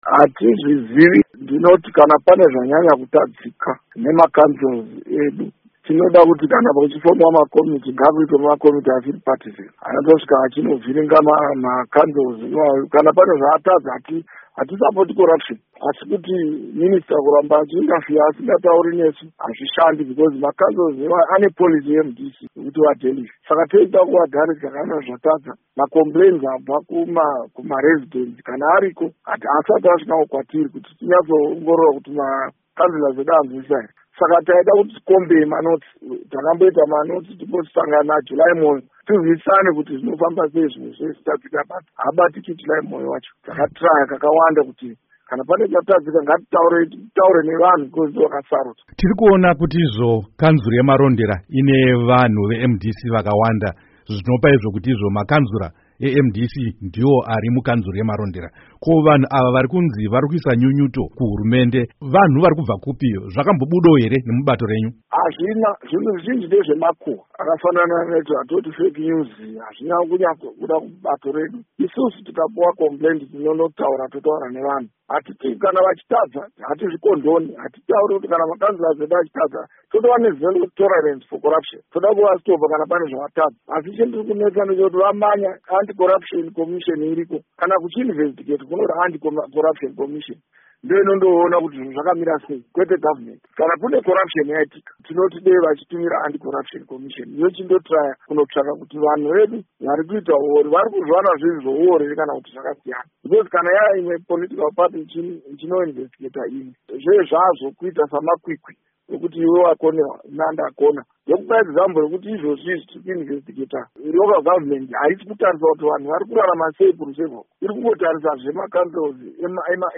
Hurukuro naVaElias Mudzuri